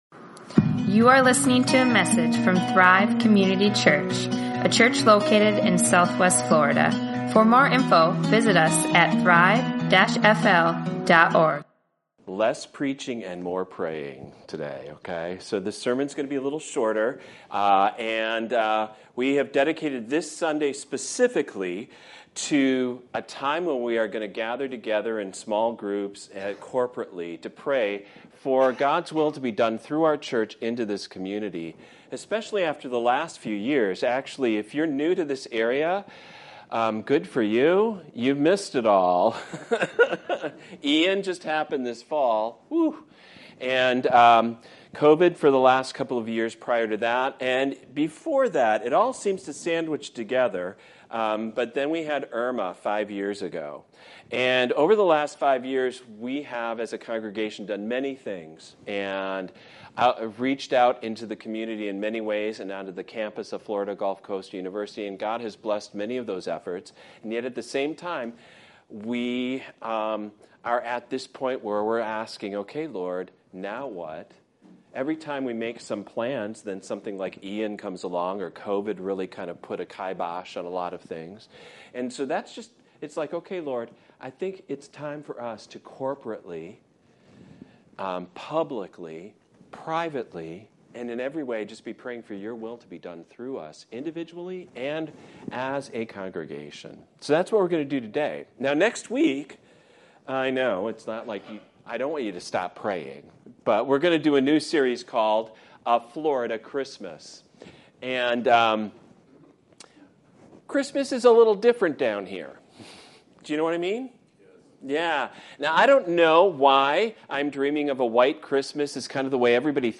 Prayer Services | Sermons | Thrive Community Church